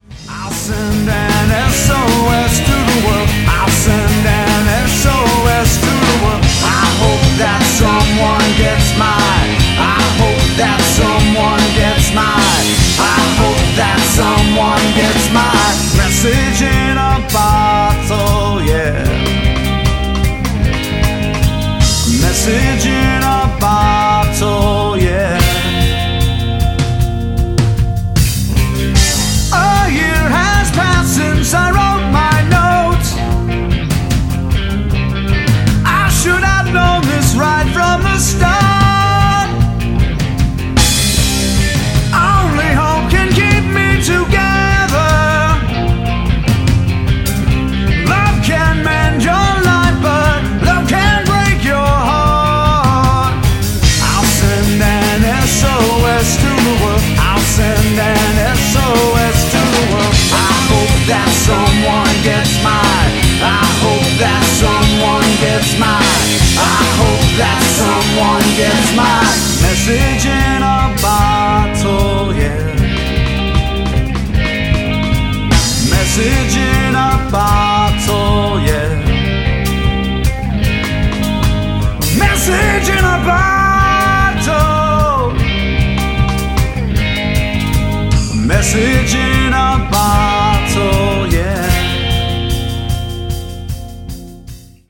*** Audio Samples (covers): ***